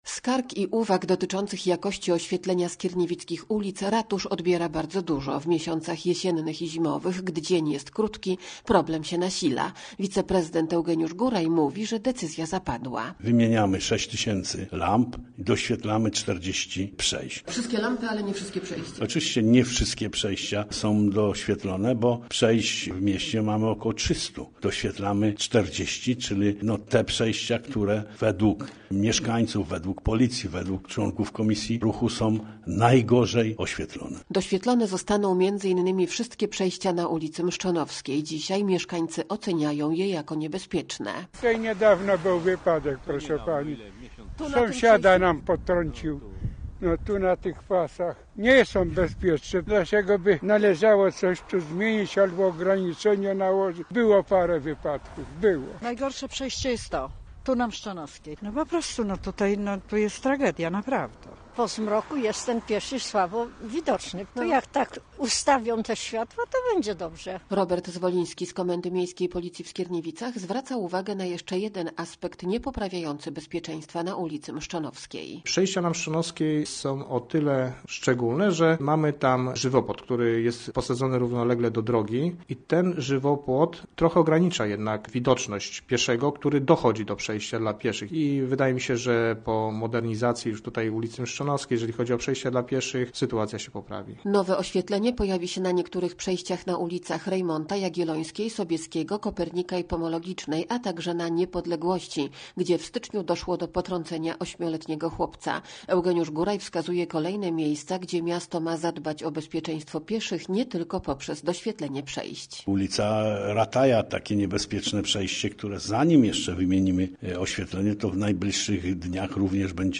Posłuchaj relacji i dowiedz się więcej: Nazwa Plik Autor Skierniewice inwestują w nowe oświetlenie audio (m4a) audio (oga) Warto przeczytać Fly Fest 2025.